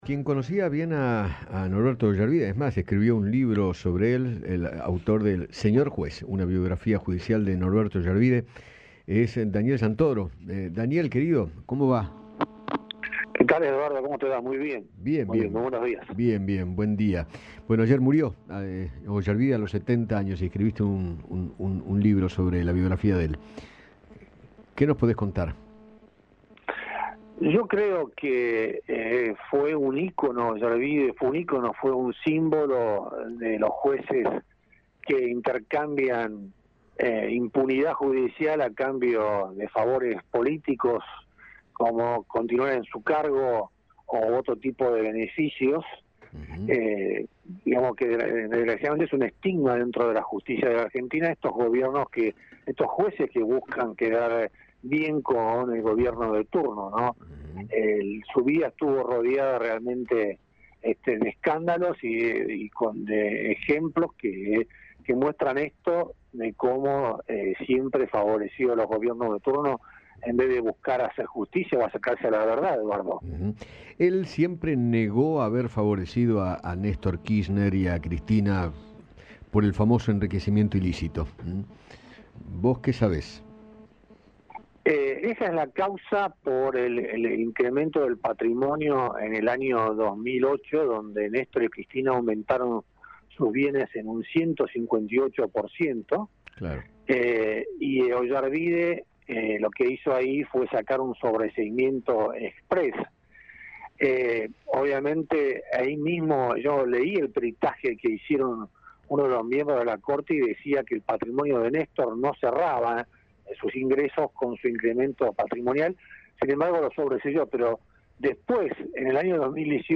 El periodista Daniel Santoro habló con Eduardo Feinmann sobre el fallecimiento del ex juez Norberto Oyarbide.